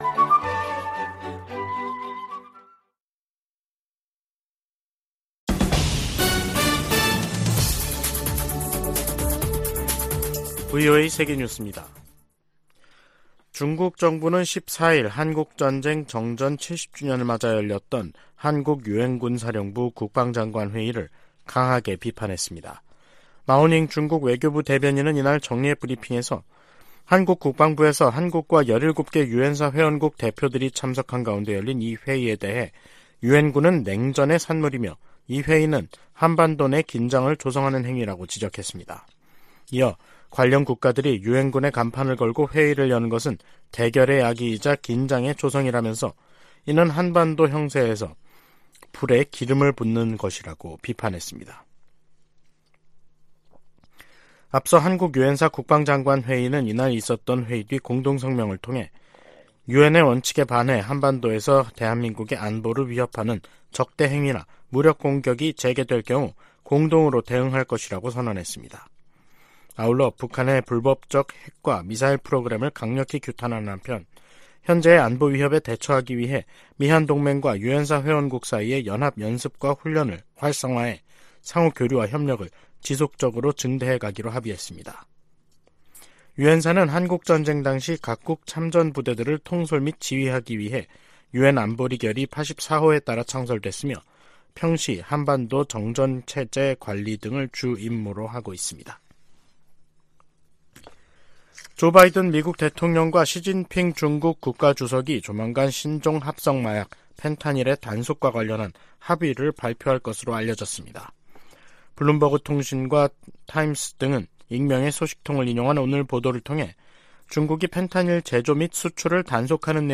VOA 한국어 간판 뉴스 프로그램 '뉴스 투데이', 2023년 11월 14일 2부 방송입니다. 한국을 방문한 로이드 오스틴 미 국방장관은 한반도 평화와 안정에 대한 유엔군사령부의 약속은 여전히 중요하다고 강조했습니다. 미 국무부는 이번 주 아시아태평양 경제협력체(APEC) 회의를 통해 내년도 역내 협력을 위한 전략적 비전이 수립될 것이라고 밝혔습니다. 15일 미중정상회담에서 양자 현안뿐 아니라 다양한 국제 문제들이 논의될 것이라고 백악관이 밝혔습니다.